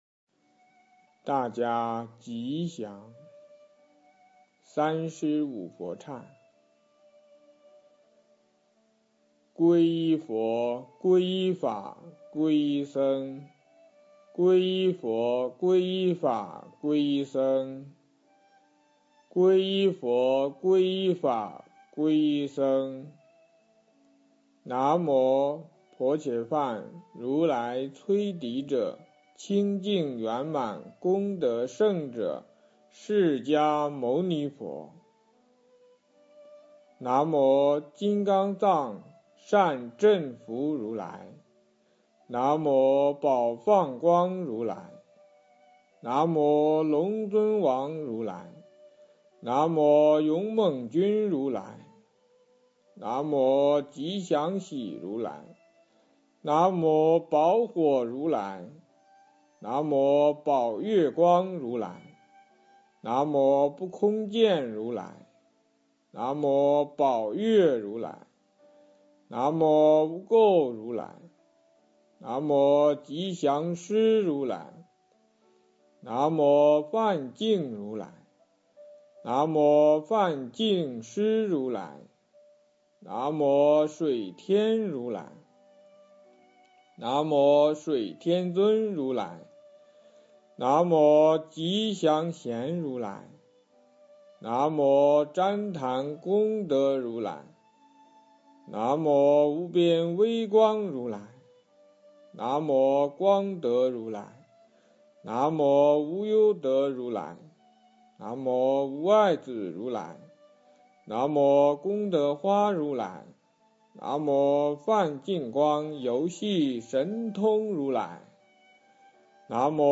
经忏
佛音 经忏 佛教音乐 返回列表 上一篇： 楞严神咒首部曲--毗卢真法会 下一篇： 往生咒--五明佛学院僧众 相关文章 貧僧有話24說：我要养成“佛教靠我”的理念--释星云 貧僧有話24說：我要养成“佛教靠我”的理念--释星云...